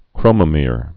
(krōmə-mîr)